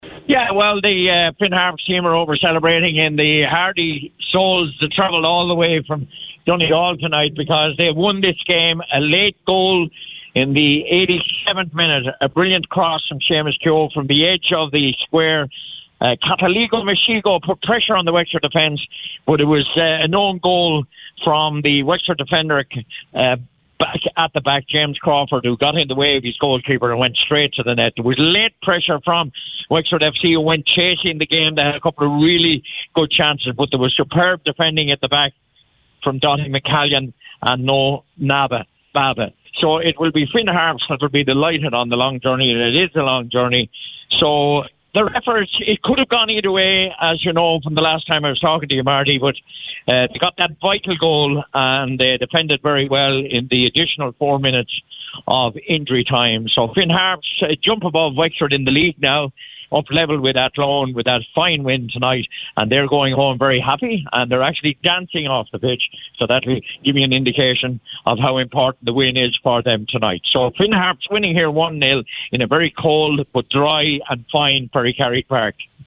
FT Report: Finn Harps win at Wexford